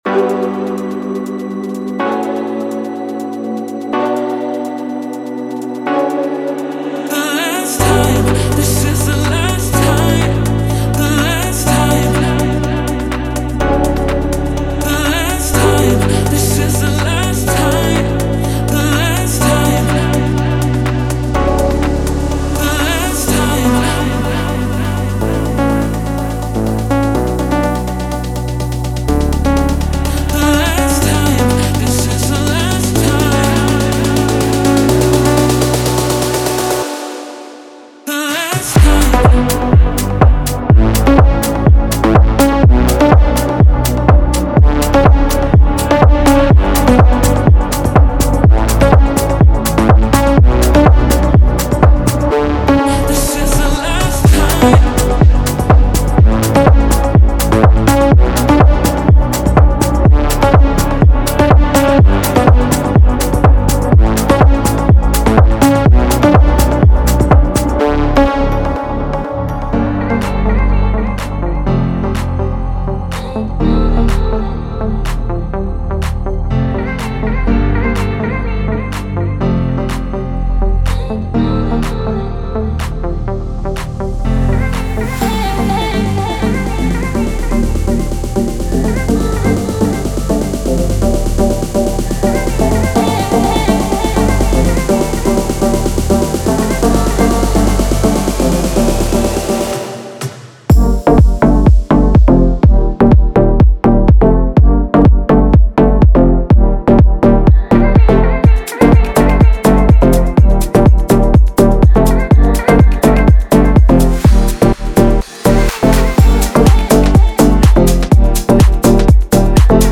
Type: Serum Samples
Deep House House Melodic Techno